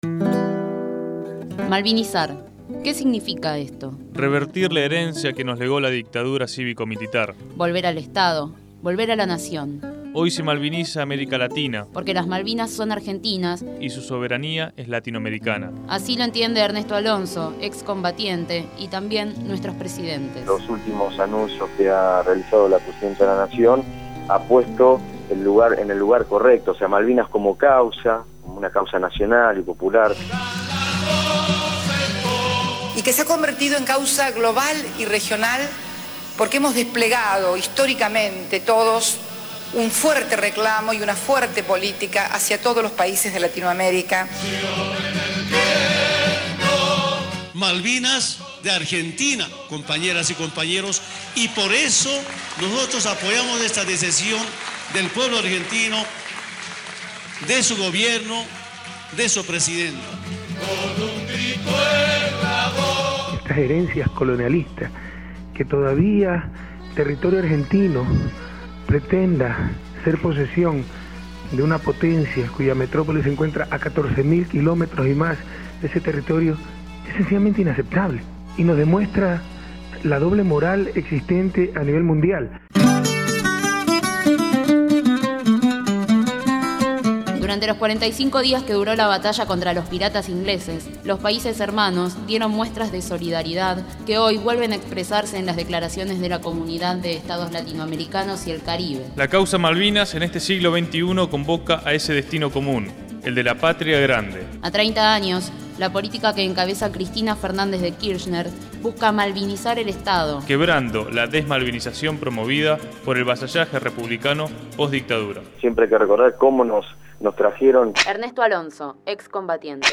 Informe sobre Malvinas